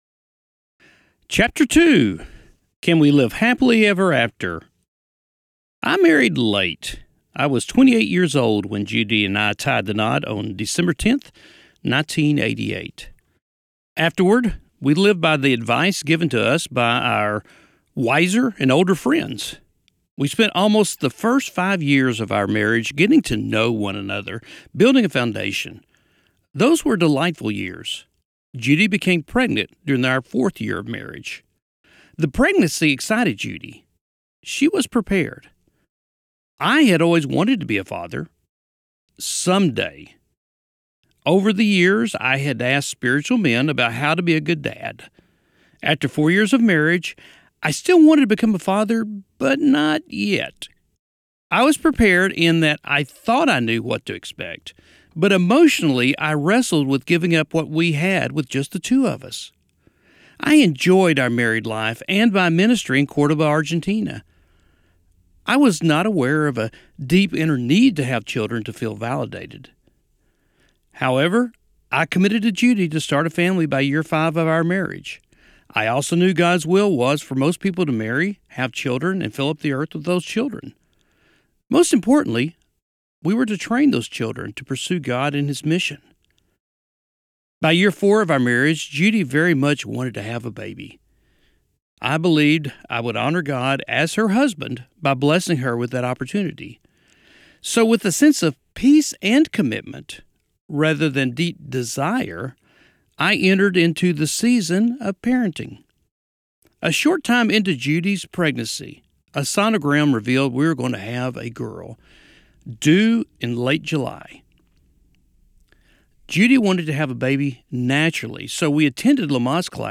Audiobook - Sample Chapter 2